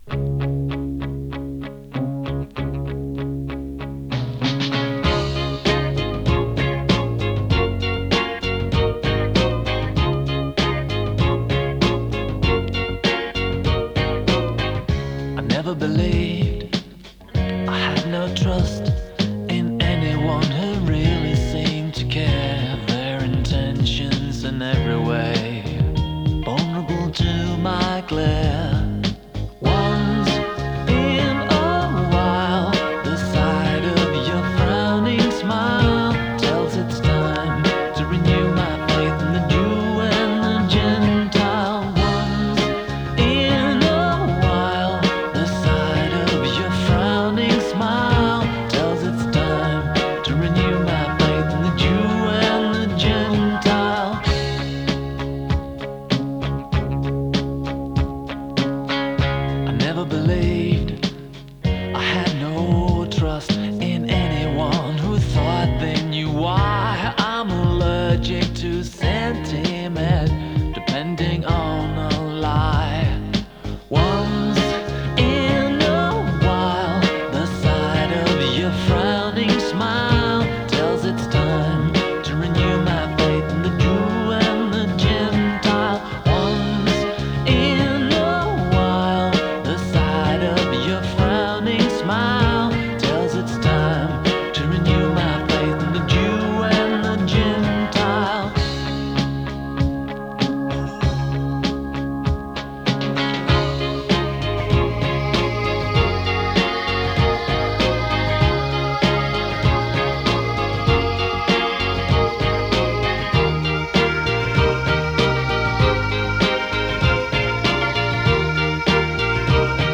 シンセポップ
心地よい浮遊感と温もりを感じさせるエレポップの傑作です！